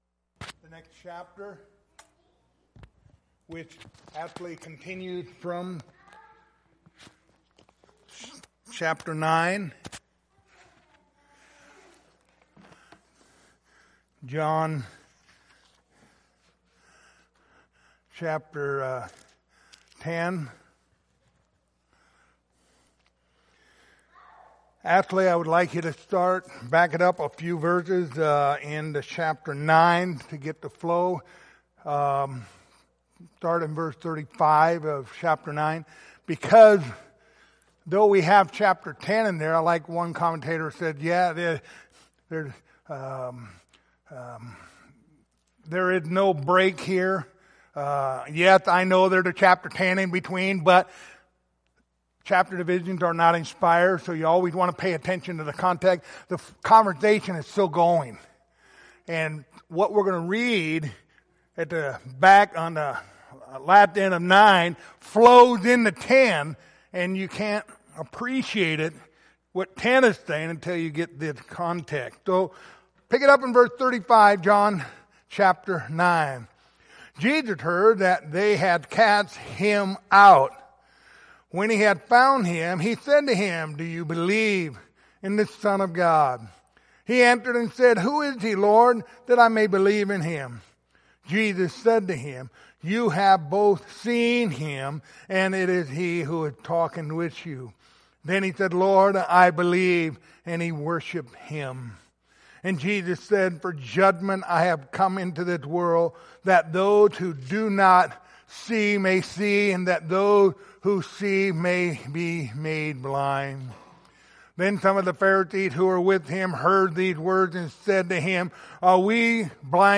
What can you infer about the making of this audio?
Passage: John 10:1-6 Service Type: Wednesday Evening Topics